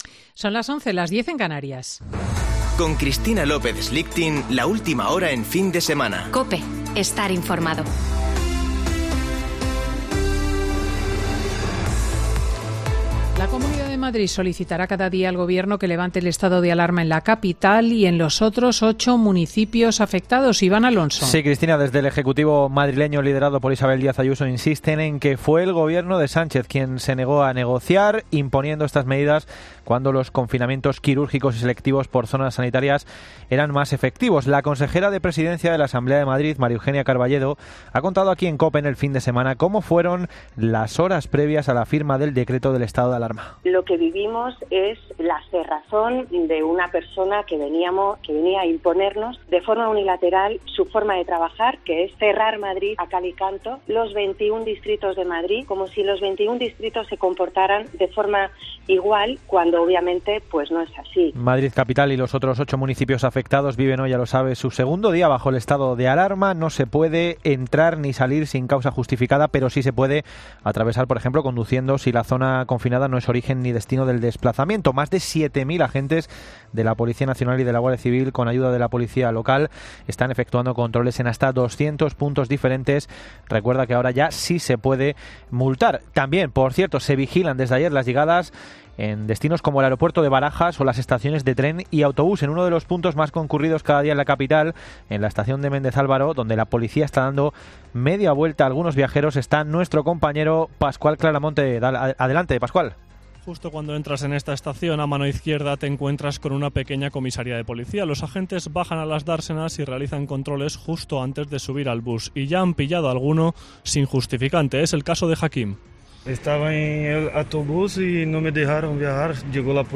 Boletín de noticias COPE del 10 de octubre de 2020 a las 11.00 horas